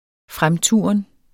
Udtale [ -ˌtuˀʌn ]